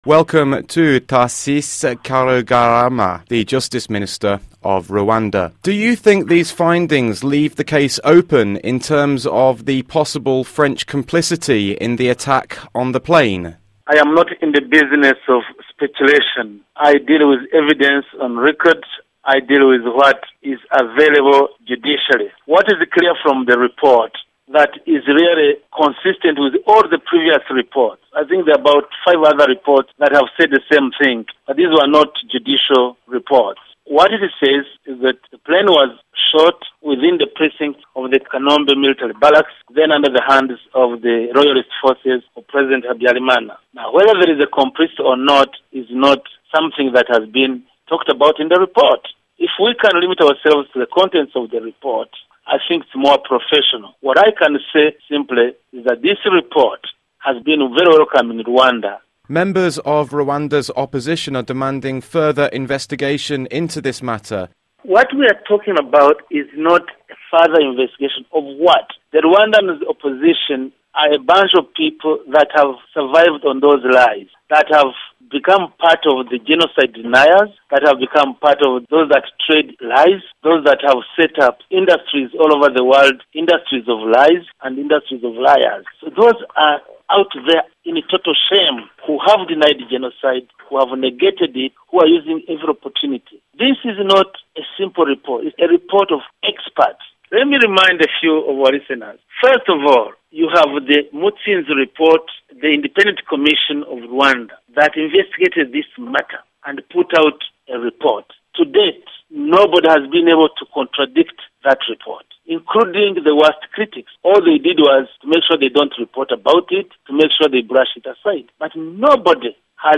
Interview: Rwandan Justice Minister Tharcisse Karugarama